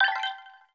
Simple Digital Connection 9.wav